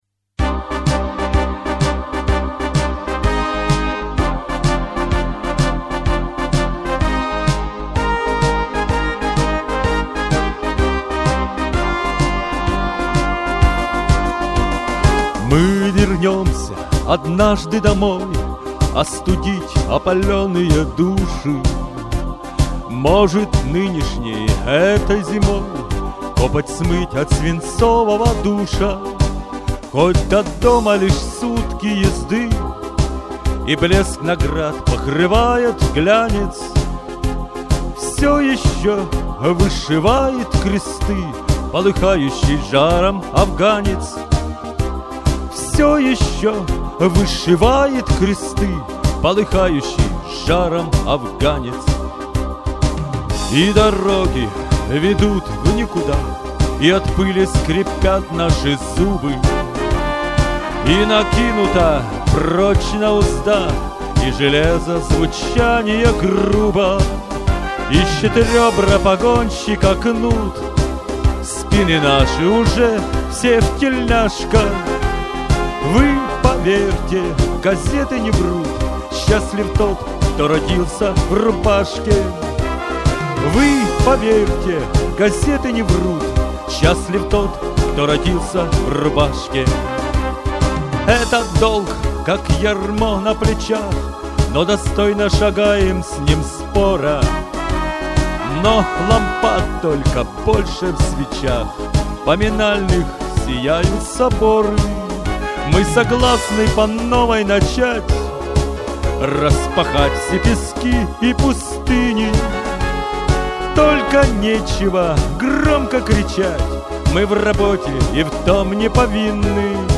• Жанр: Военная